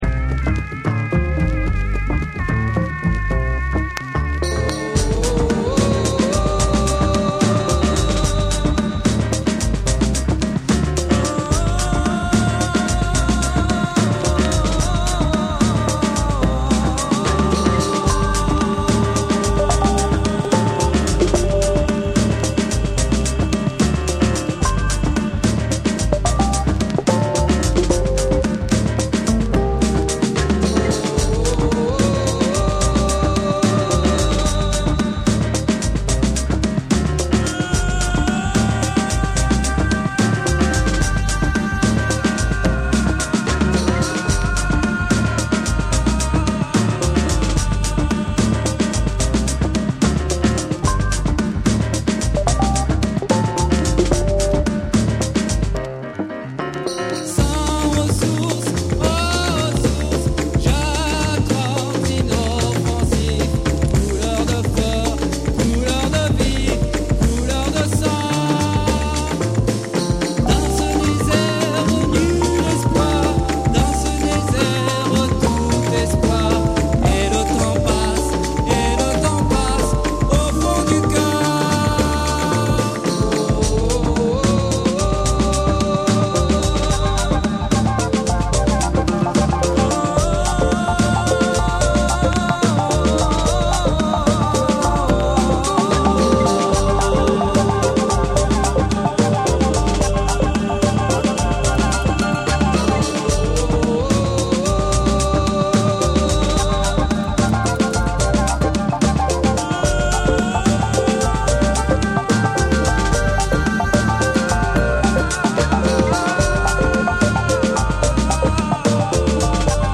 JAPANESE / BREAKBEATS